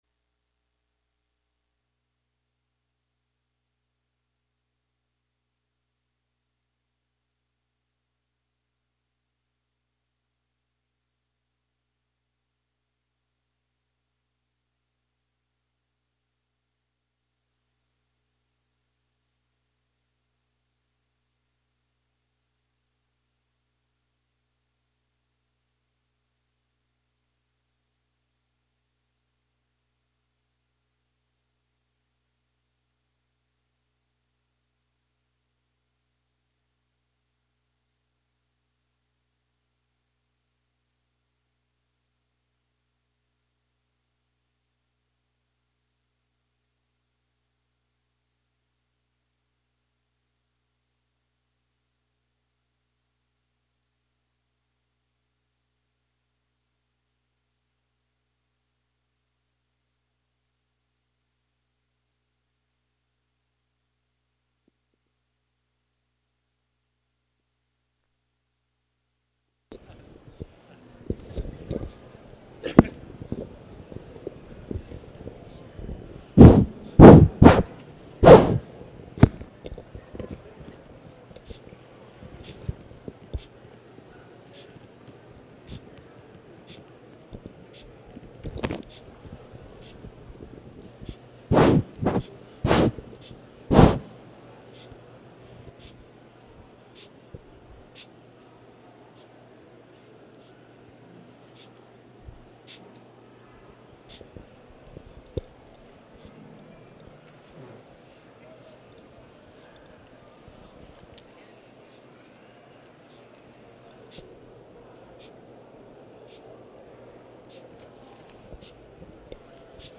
تاريخ النشر ٨ صفر ١٤٤٠ هـ المكان: المسجد الحرام الشيخ